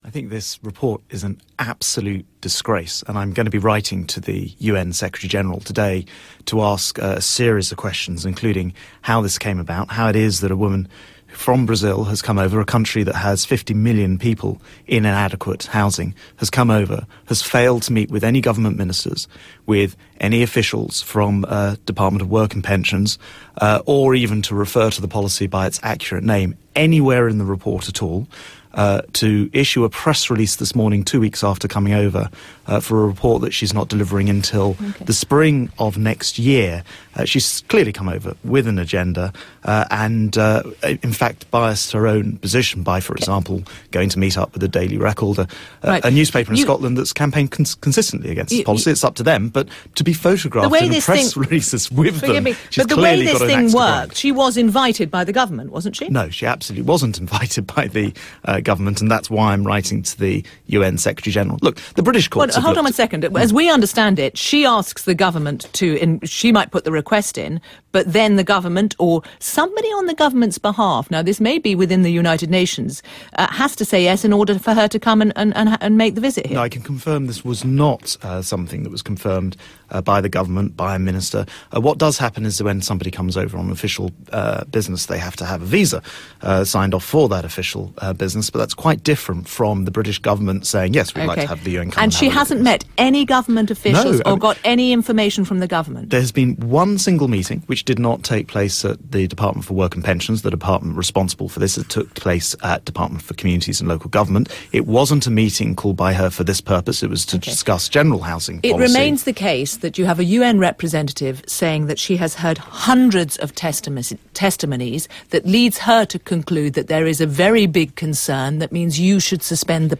The Conservative Party Chairman rejects the UN Special Rapporteur on housing's 'biased' report in an interview with Radio 4's Today programme on 11 September 2013.